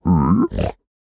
minecraft / sounds / mob / piglin / idle4.ogg